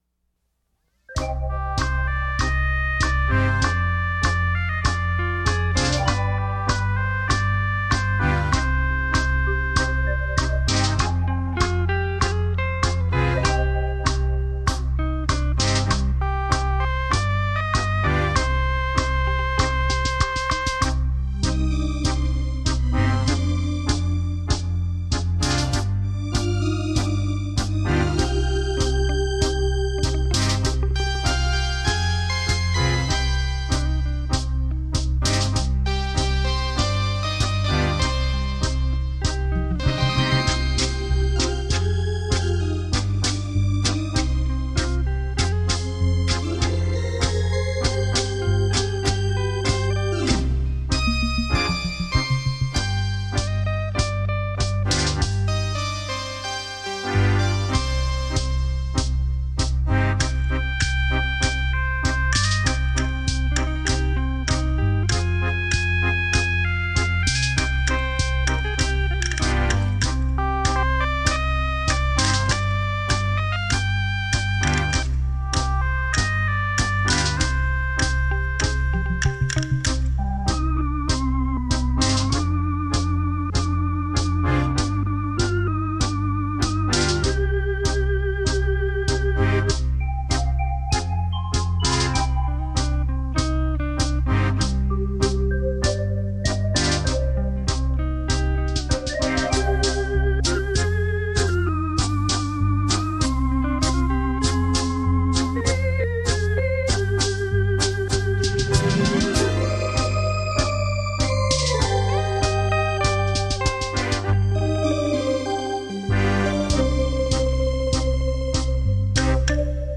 原音重现·超立体现场演奏